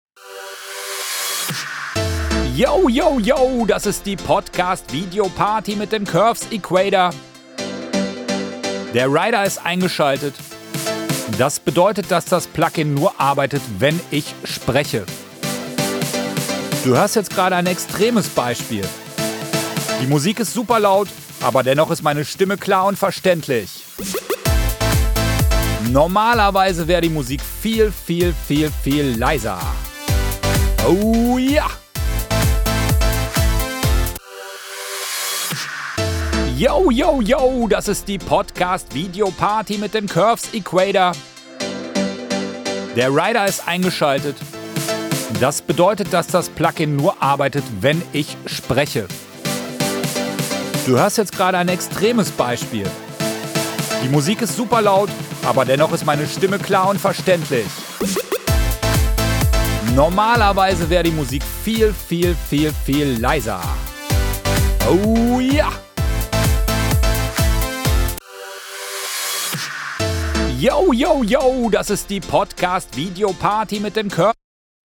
Der Curves Equator arbeitet ähnlich wie ein Multiband-Kompressor und senkt nur die Frequenzanteile der Stimme in der Musik ab.
Audio Ducking mit aktiviertem Curves Equator
audio-ducking-mit-aktiviertem-curves-equator.mp3